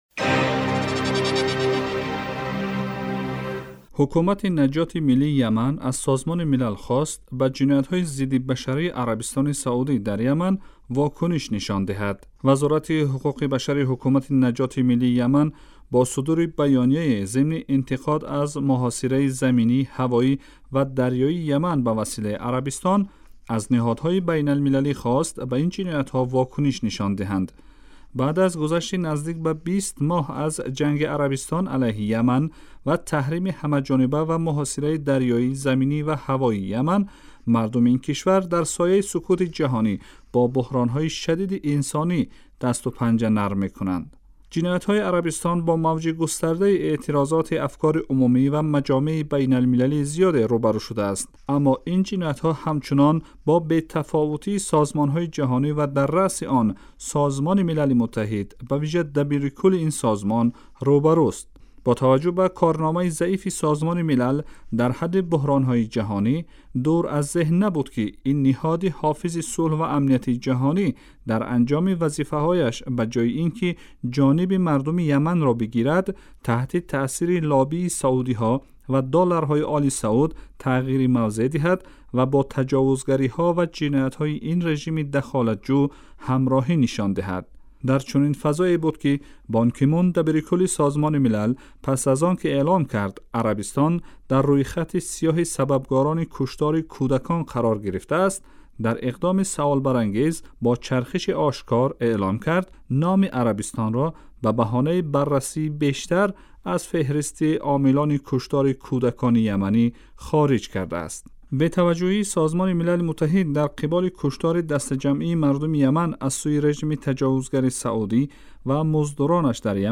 Радио